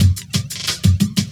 17 LOOP07 -R.wav